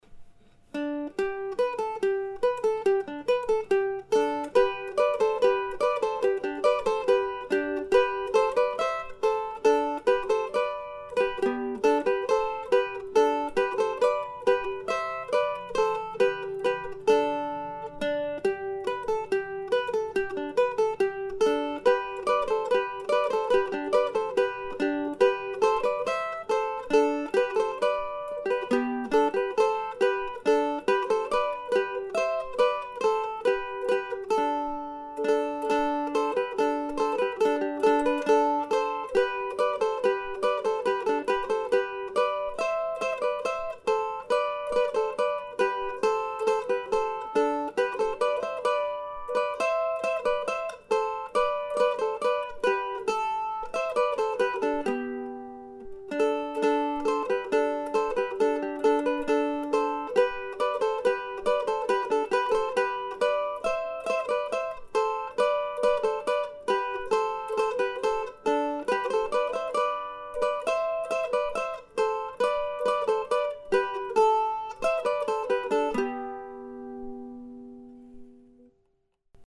CMSA 2006 Workshop: 18th Century English and Scottish Music for Mandolin
(Here is a home recording of the
Duettino XV that was played during the workshop.)